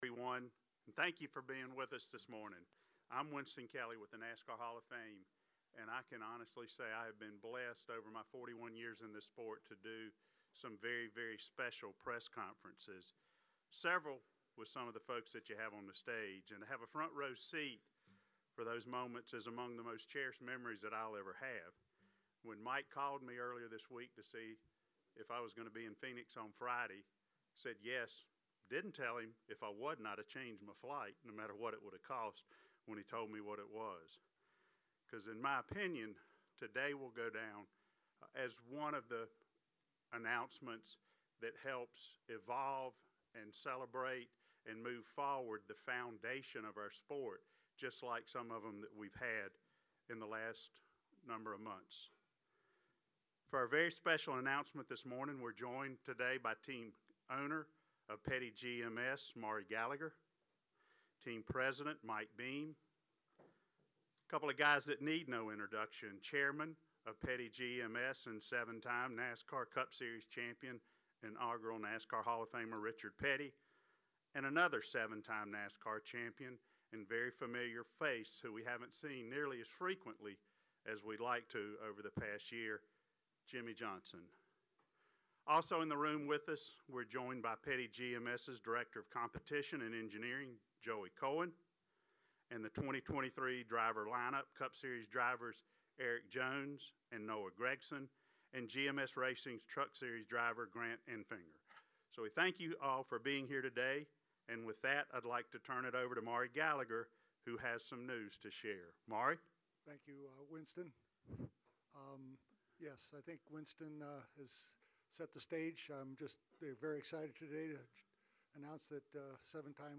Seven-time NASCAR Cup Series champion Jimmie Johnson has joined Petty GMS as a co-owner and part-time driver in a deal revealed Friday morning at a press conference in the Phoenix Raceway media center ahead of Sunday’s NASCAR Cup Series Championship Race (3 p.m. […]
PettyGMSAnnouncement.mp3